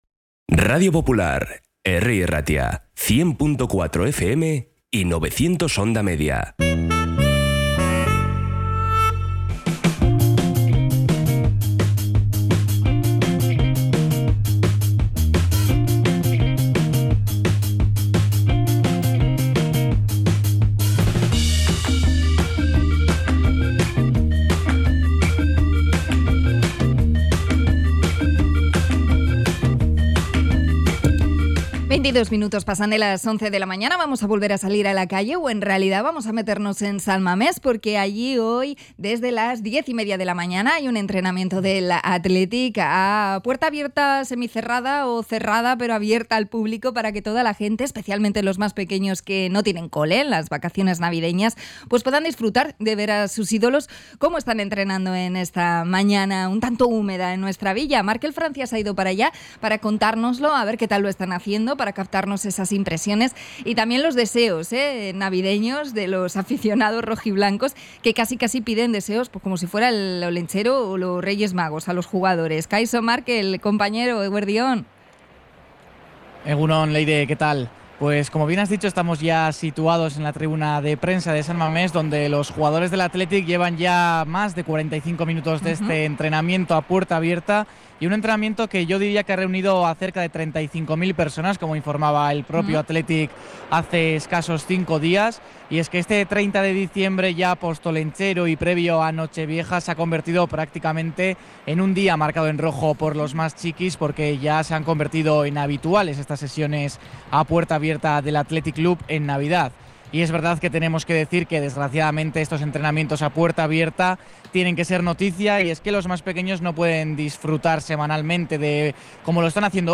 Conexión con san Mamés por el entrenamiento del Athletic